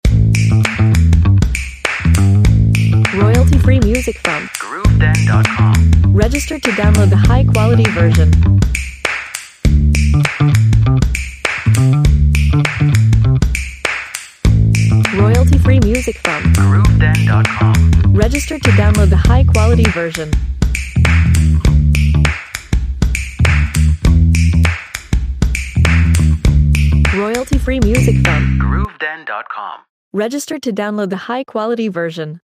Instruments: Guitar bass, percussion, clap.